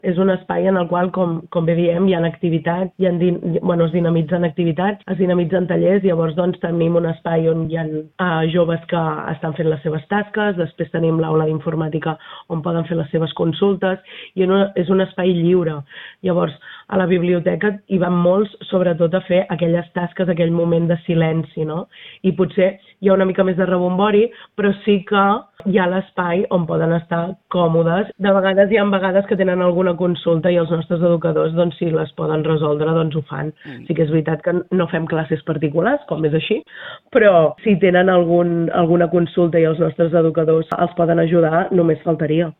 Mariceli Santarén és la regidora de Joventut de l’Ajuntament de Calella.